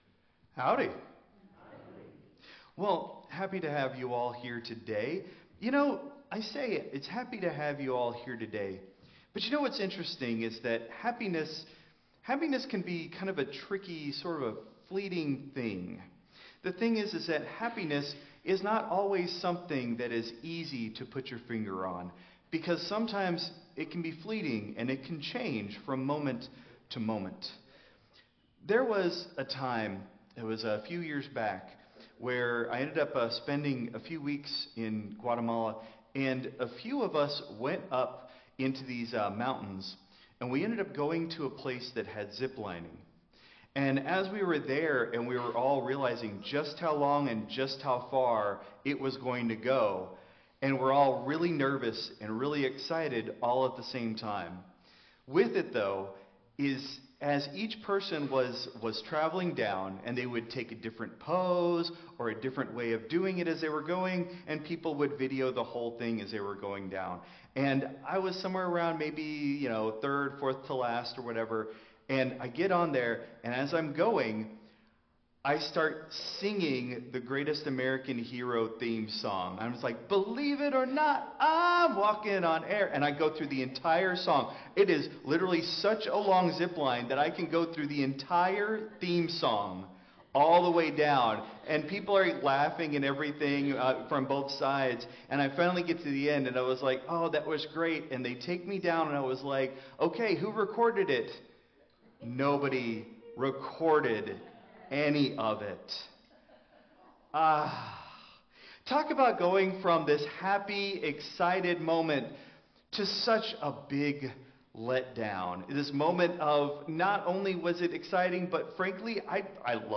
Christ Memorial Lutheran Church - Houston TX - CMLC 2024-12-15 Sermon (Traditional)